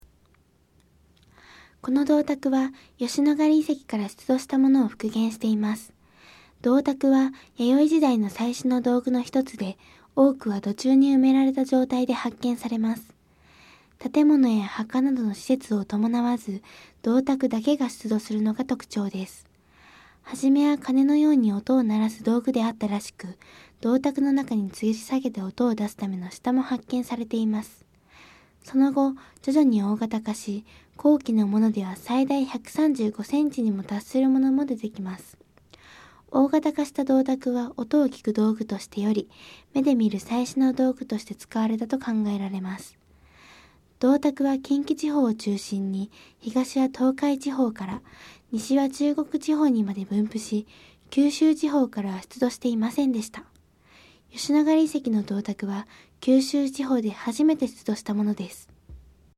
吉野ヶ里遺跡の銅鐸は九州地方で初めて出土したものです。 音声ガイド 前のページ 次のページ ケータイガイドトップへ (C)YOSHINOGARI HISTORICAL PARK